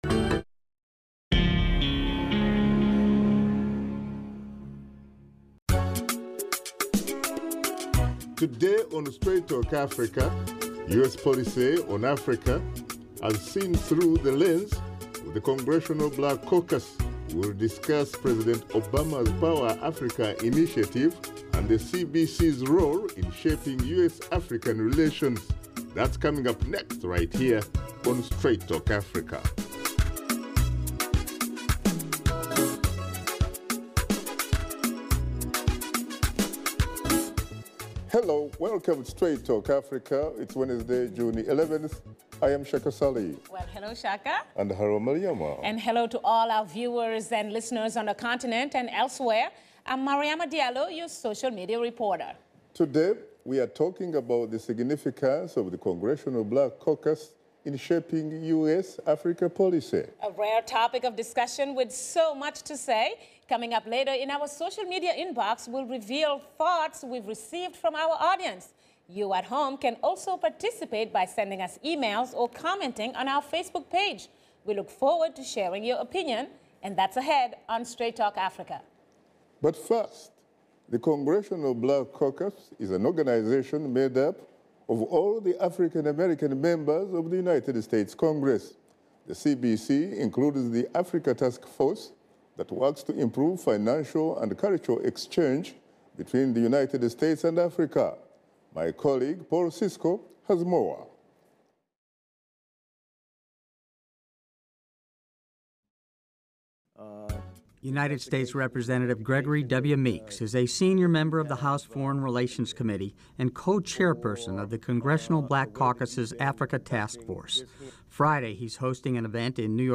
Host Shaka Ssali and his guests discuss the role of the Congressional Black Caucus in foreign policy and initiatives in Africa.